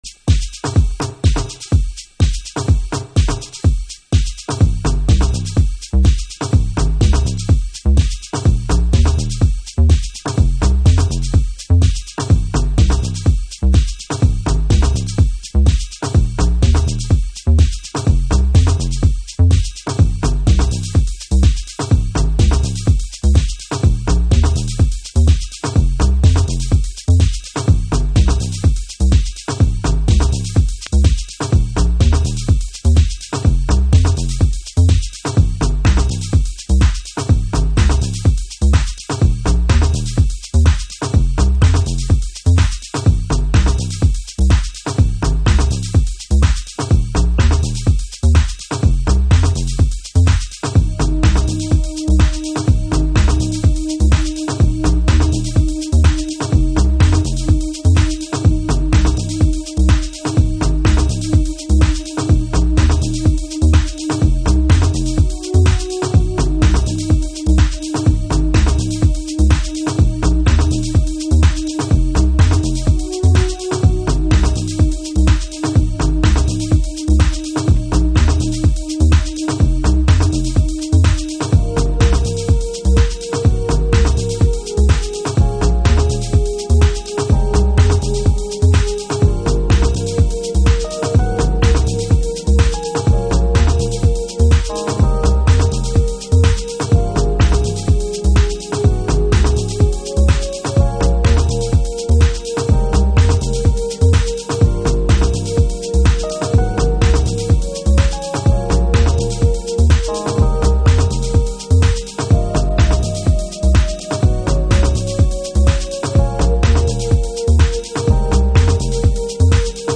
Chicago Oldschool / CDH